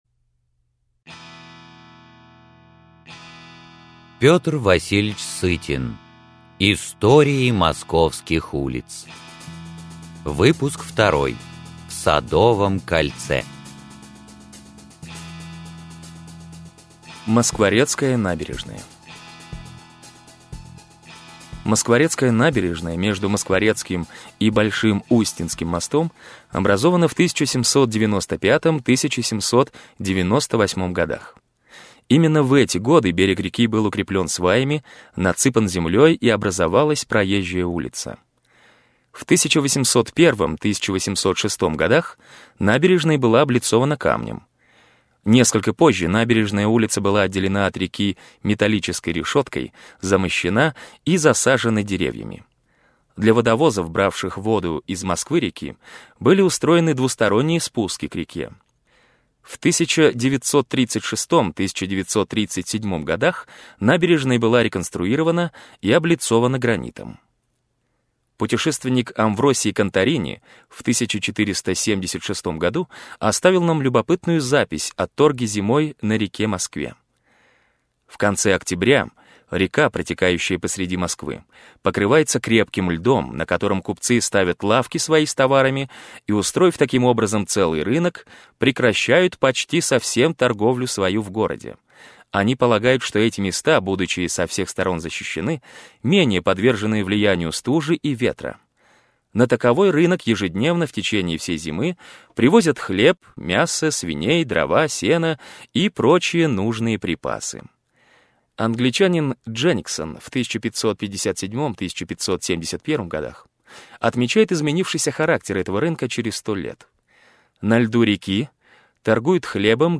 Аудиокнига Истории московских улиц. Выпуск 2 | Библиотека аудиокниг